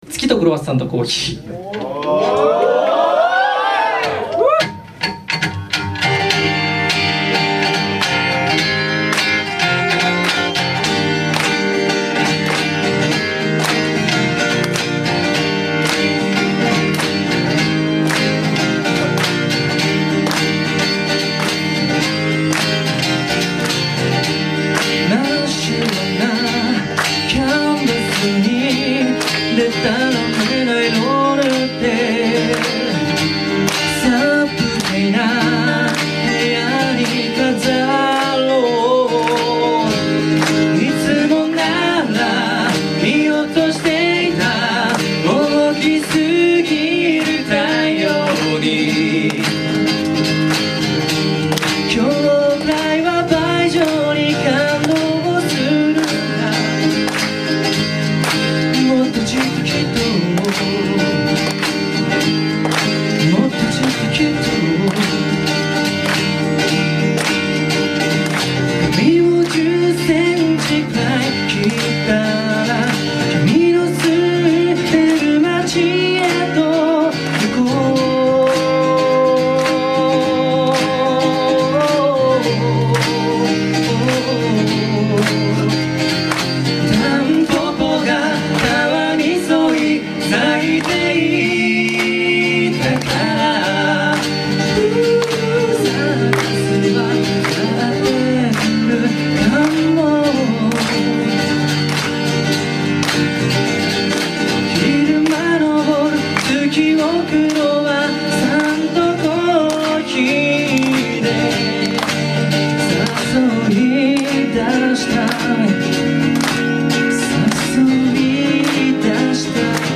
基本的にレコーディング音源も５〜７年前の自主制作なので、音は悪いです。
ライブ音源なんかもっと悪いです。